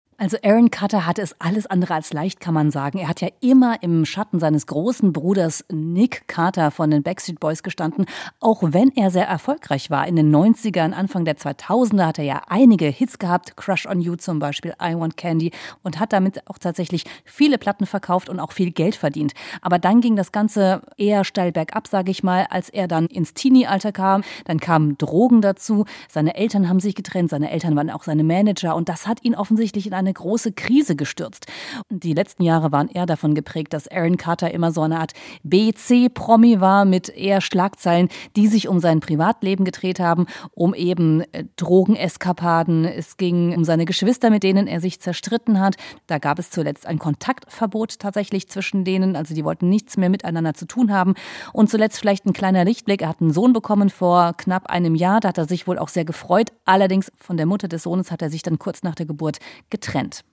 Nachrichten 7.11.2022 Backstreet Boys erinnern bei Londoner Konzert an Aaron Carter